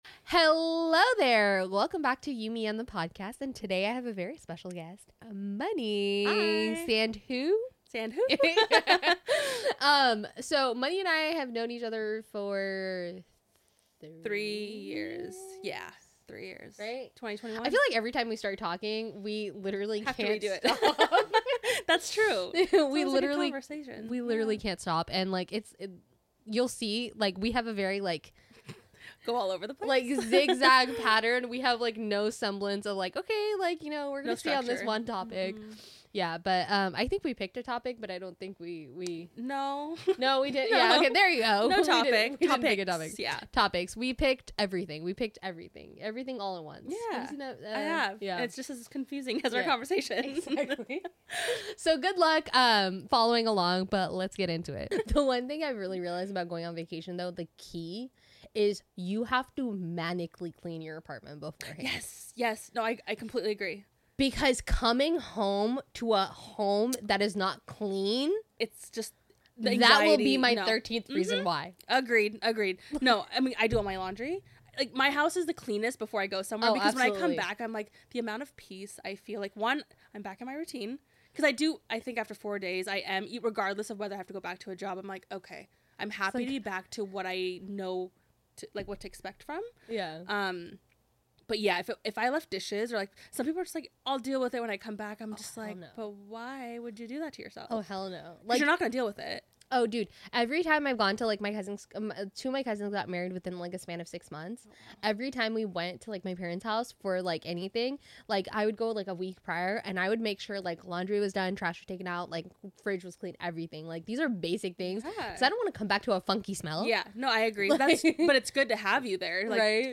Listen in for an empowering and insightful conversation!